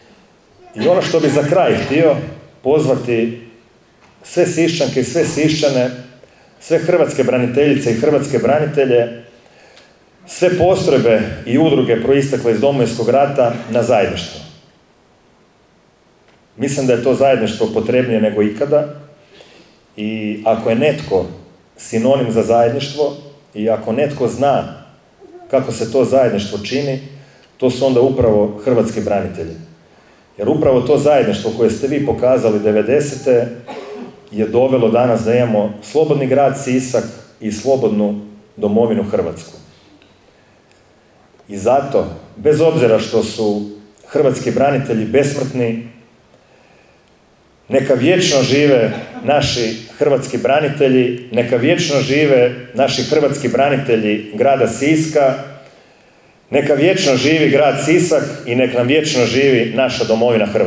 Hrvatskim braniteljima grada Siska njihov dan čestitao je i gradonačelnik Domagoj Orlić